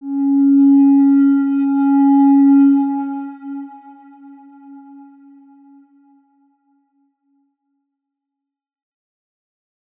X_Windwistle-C#3-ff.wav